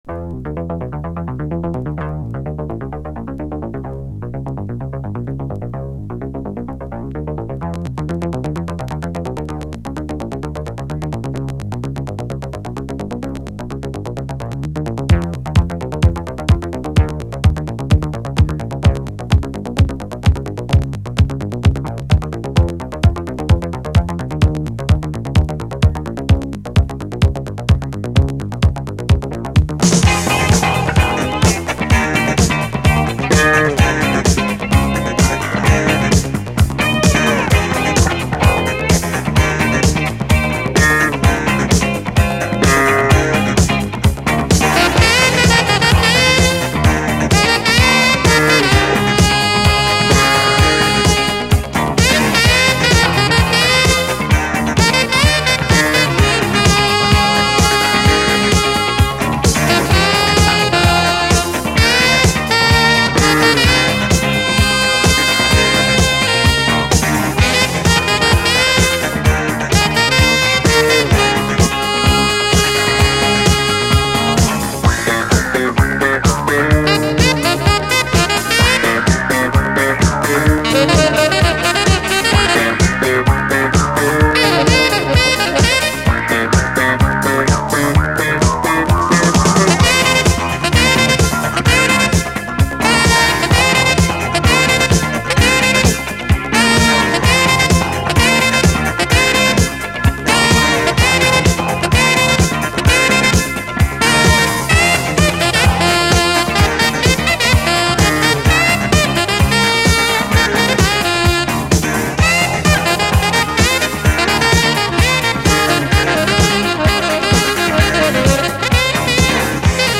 JAZZ FUNK / SOUL JAZZ, DISCO, JAZZ
意外なイタロ・テイストと共に熱く疾走する最高ロッキン・ディスコ！